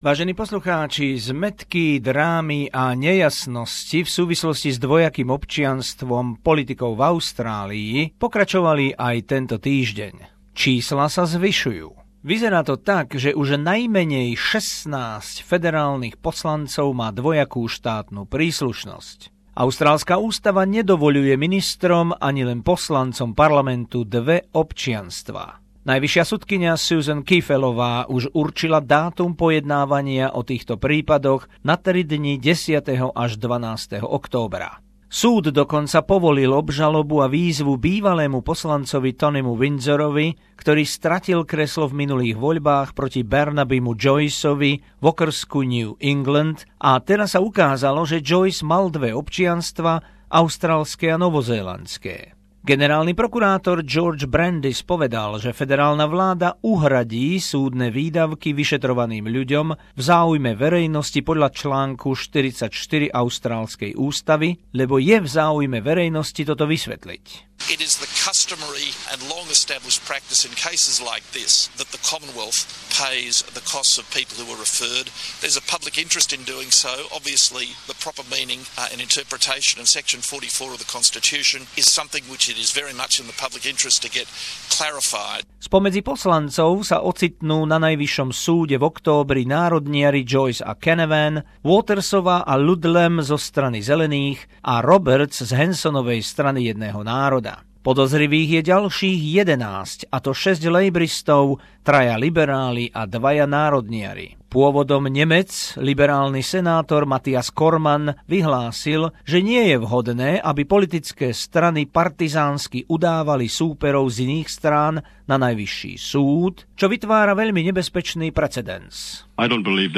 Spory o dvojitom občianstve poslancov a ministrov v Austrálii ukončí Najvyšší súd 10.-12. októbra. Zo spravodajskej dielne SBS.